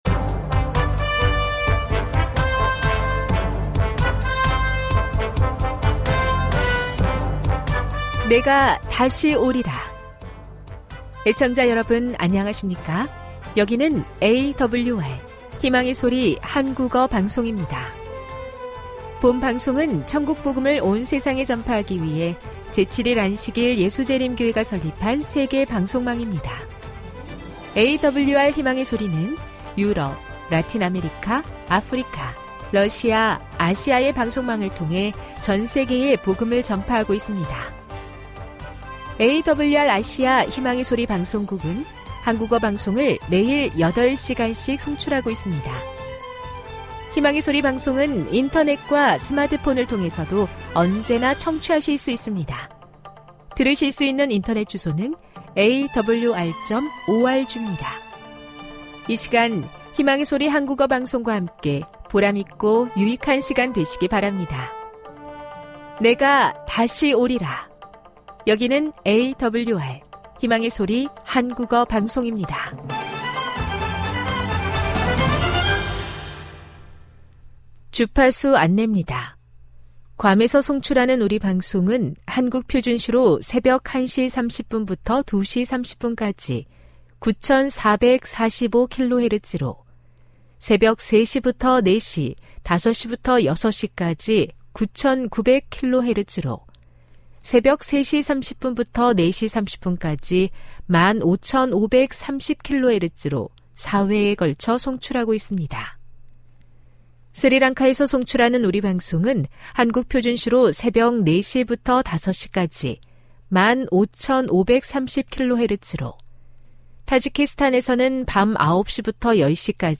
1 설교, 걸어서 성경속으로 58:42